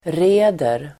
Uttal: [r'e:der]